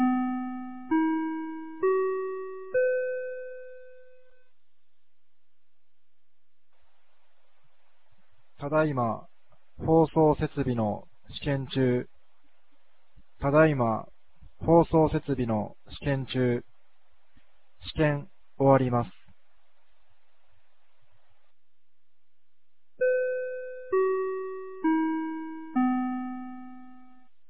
2024年08月17日 16時04分に、由良町から全地区へ放送がありました。